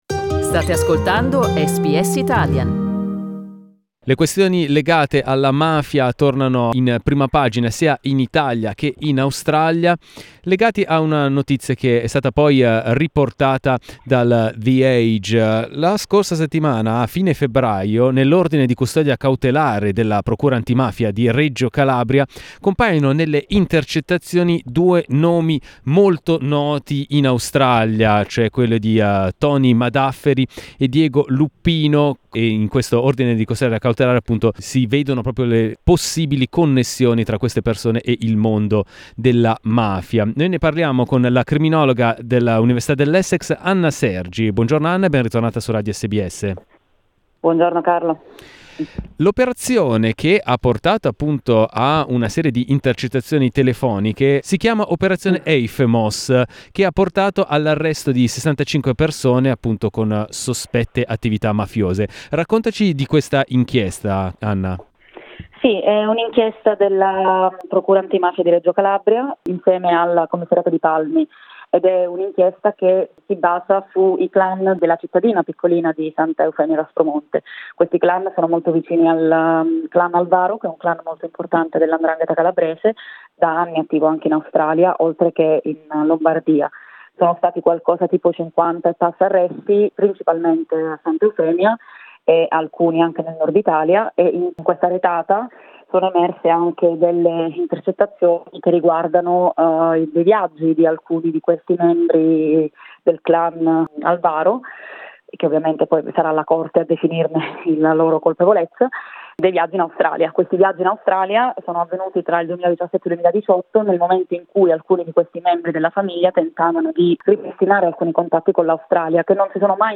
Senior Lecturer in Criminology